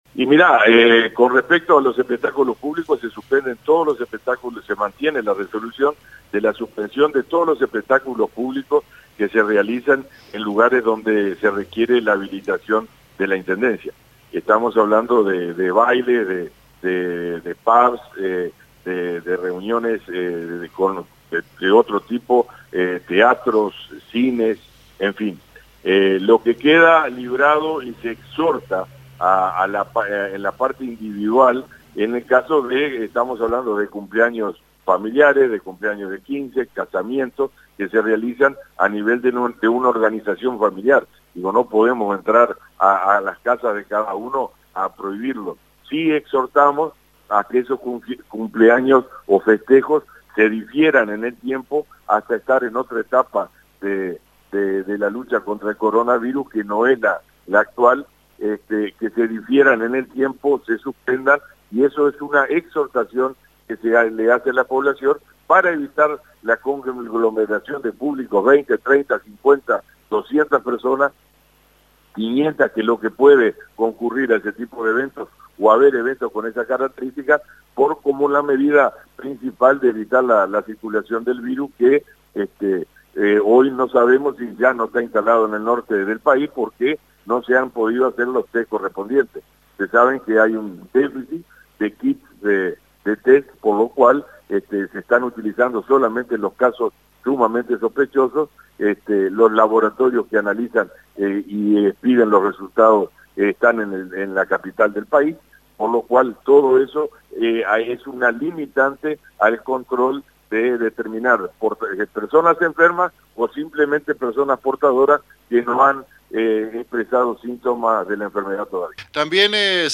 El intendente departamental José Omar Menéndez en entrevista telefónica con AM 1110 informó las medidas que se tomaron el día de ayer en el Congreso de Intendentes.